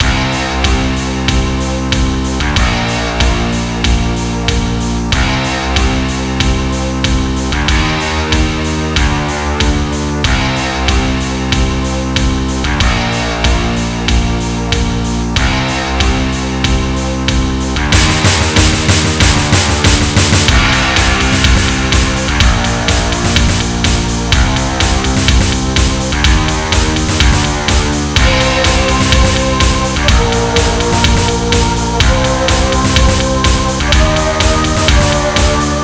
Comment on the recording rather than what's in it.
Extended Module